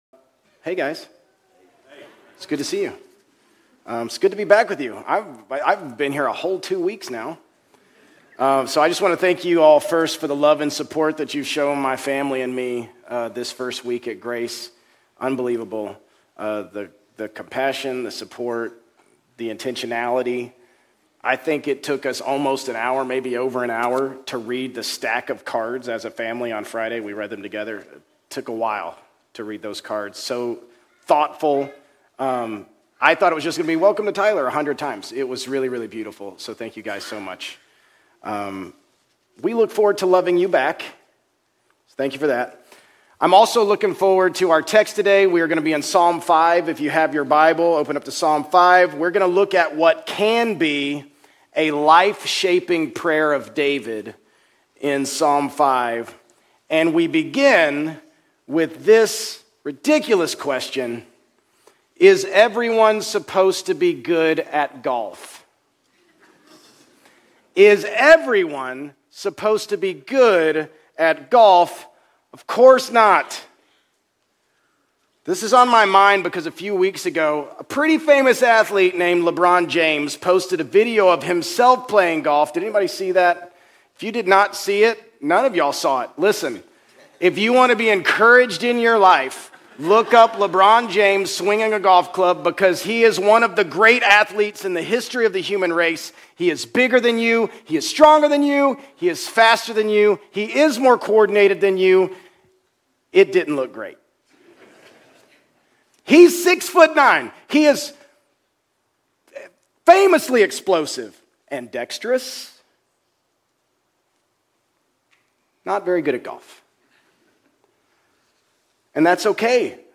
Grace Community Church Old Jacksonville Campus Sermons 8_17 Old Jacksonville Campus Aug 17 2025 | 00:36:55 1x 00:00 / 00:36:55 Subscribe Share RSS Feed Share Link Embed